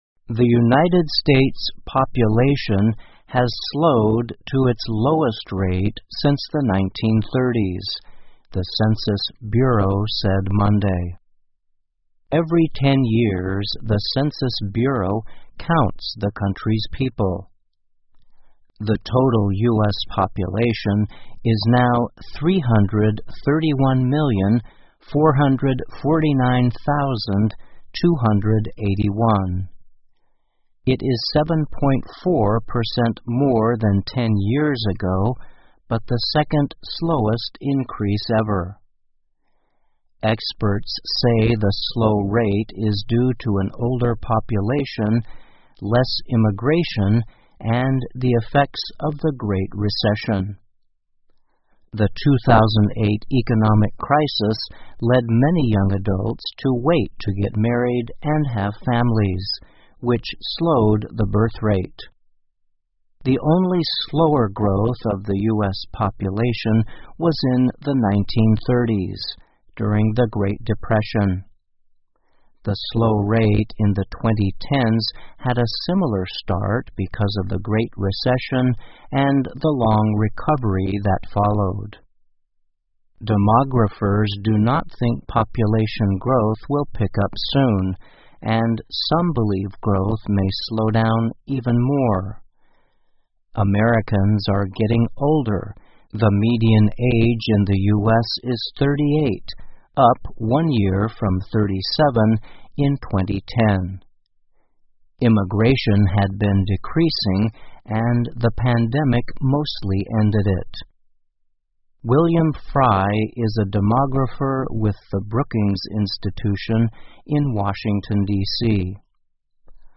VOA慢速英语2021--美国人口增长放缓 听力文件下载—在线英语听力室